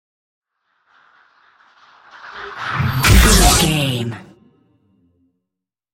Sci fi shot whoosh to hit
Sound Effects
futuristic
whoosh